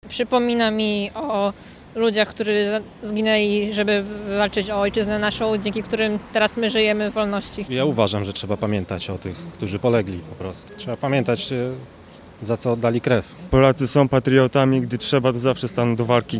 Jednocześnie pod obeliskiem Powstania Warszawskiego na olsztyńskim Zatorzu rozpoczęły się uroczystości upamiętniające wybuch powstania.